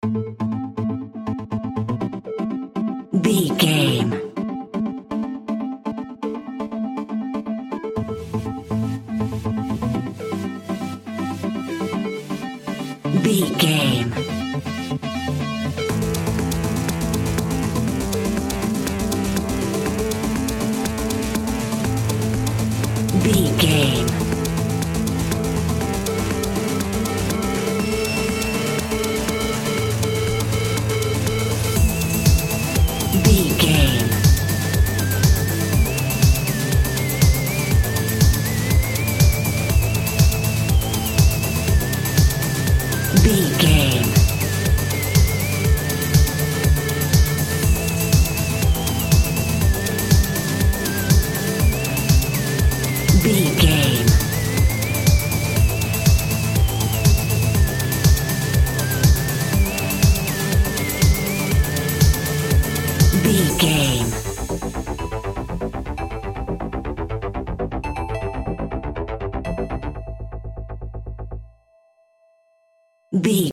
Pop Electronic Dance Music 60 Sec.
Epic / Action
Fast paced
Aeolian/Minor
Fast
groovy
uplifting
energetic
bouncy
synthesiser
drum machine
house
instrumentals
synth bass
upbeat